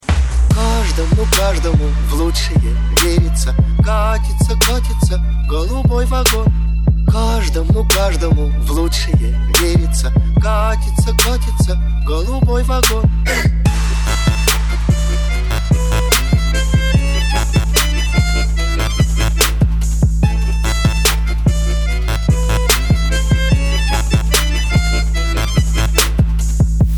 Trap remix